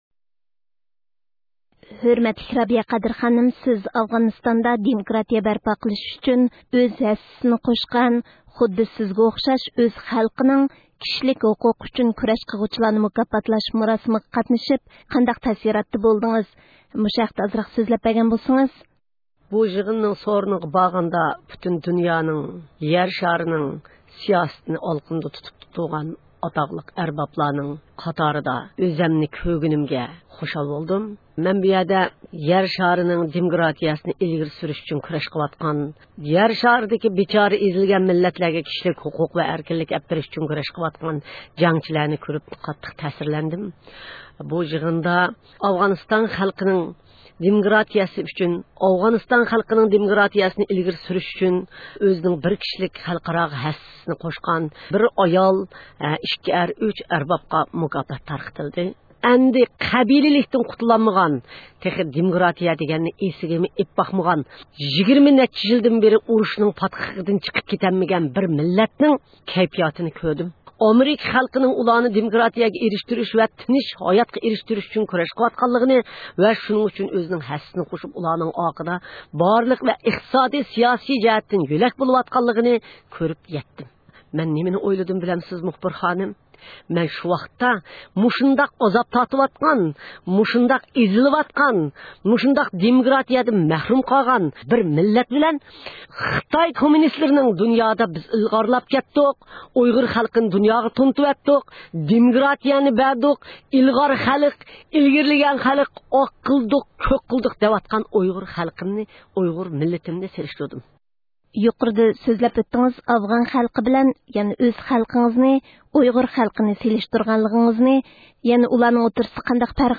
NED «دېموكراتىيە» مۇكاپاتى مۇراسىمىدىن كېيىن رابىيە خانىمنى زىيارەت – ئۇيغۇر مىللى ھەركىتى